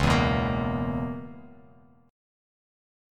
C#m13 chord